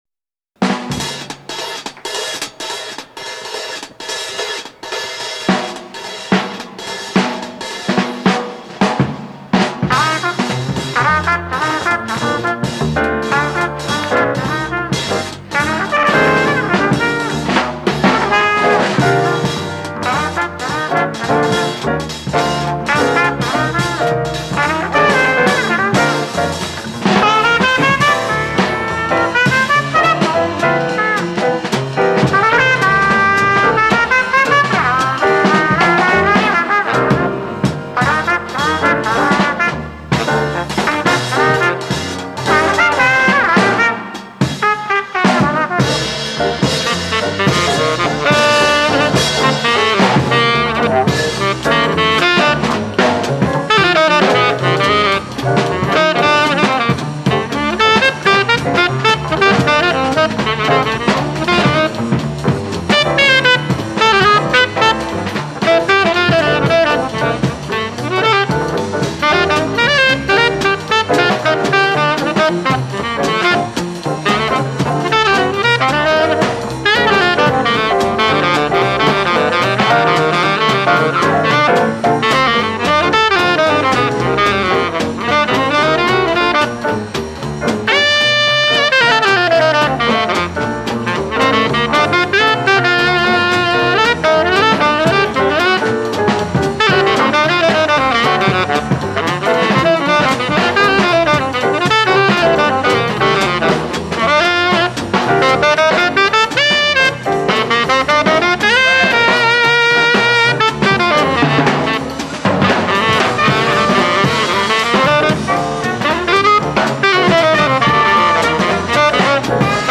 in an all-star jam session, recored live in Nice, France
Bop
tenor Sax
drums